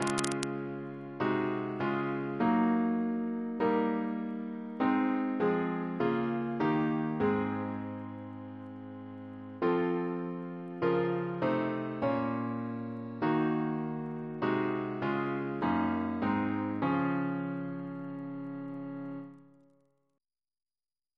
CCP: Chant sampler
Double chant in D♭ Composer: James Turle (1802-1882), Organist of Westminster Abbey Reference psalters: ACB: 120; ACP: 13; CWP: 76; H1982: S438; OCB: 165; RSCM: 143